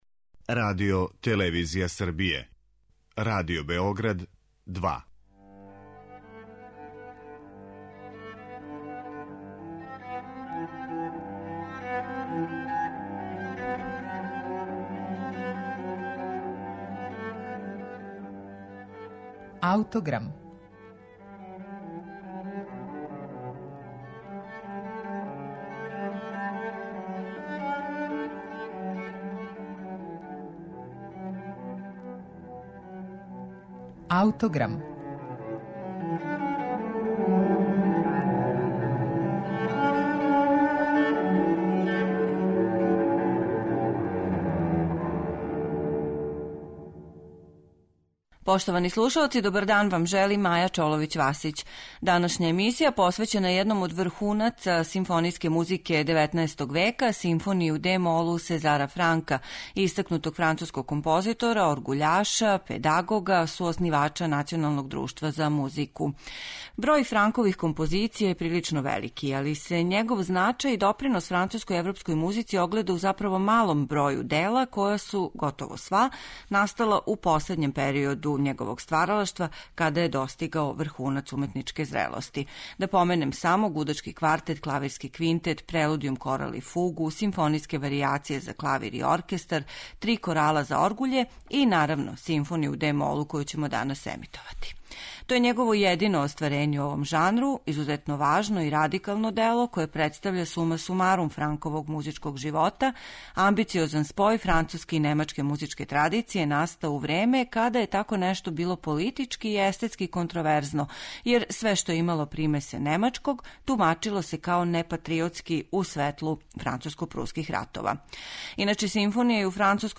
роматничарске сифмонијcкe литературе